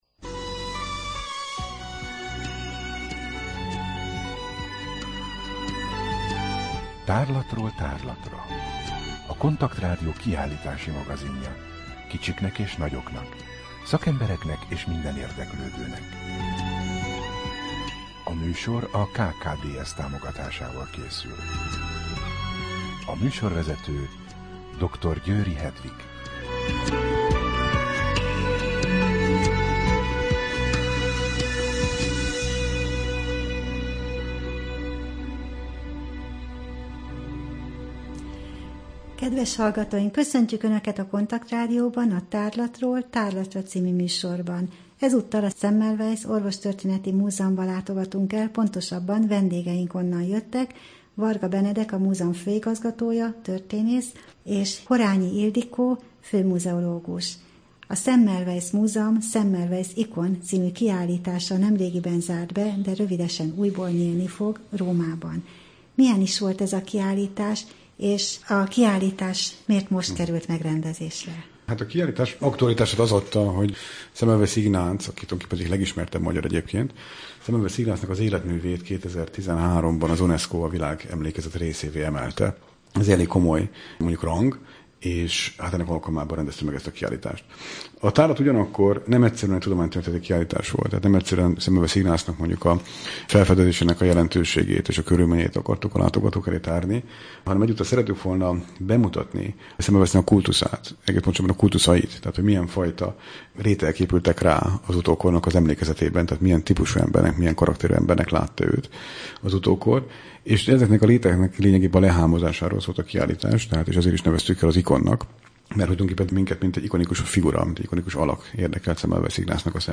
Rádió: Tárlatról tárlatra Adás dátuma: 2013, December 2 Tárlatról tárlatra / KONTAKT Rádió (87,6 MHz) 2013. december 2.